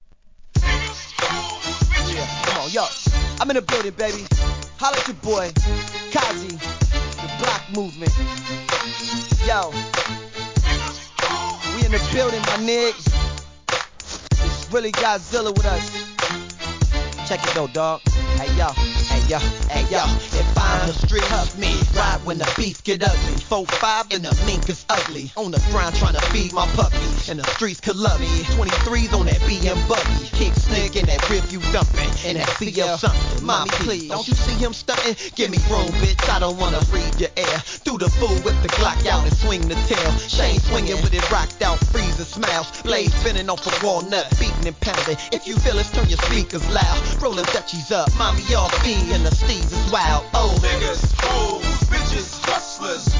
G-RAP/WEST COAST/SOUTH
クラップ音に。シンセ音で両面ともにCLUB栄え抜群!!